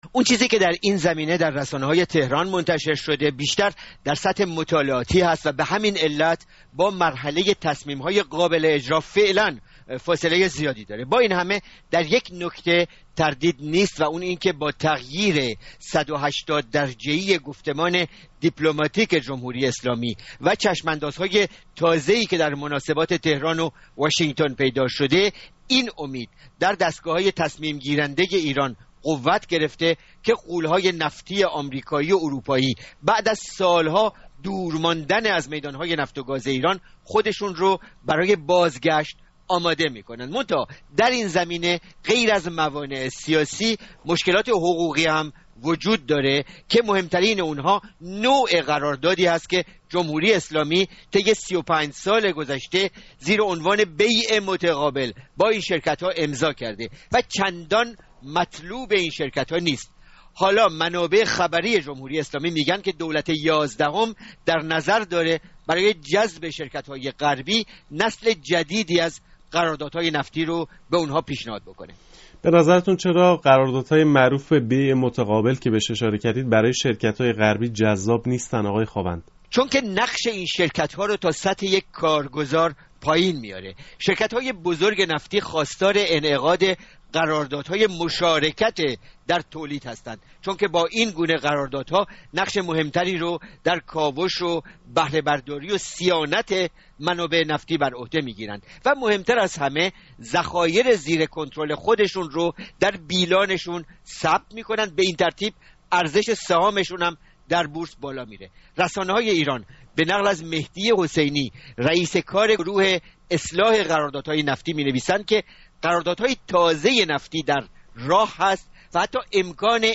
گفتگوی رادیو فردا